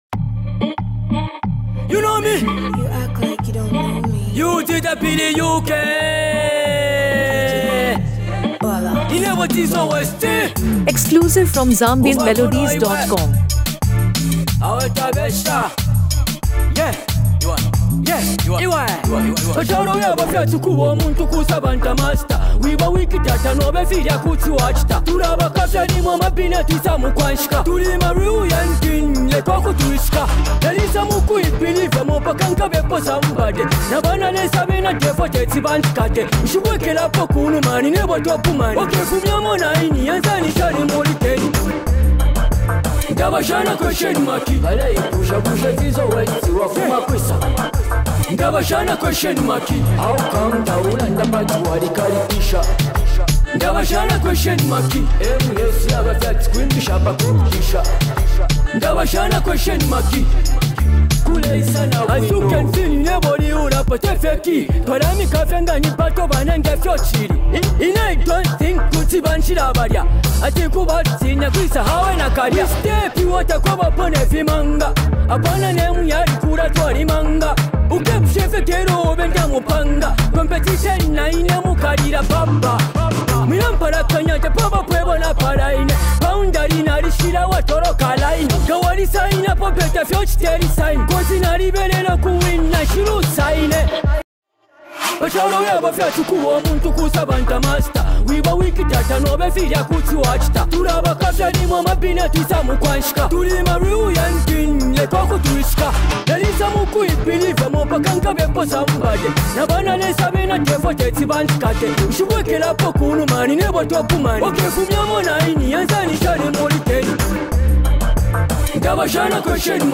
Copperbelt Zambian Hip-Hop Hit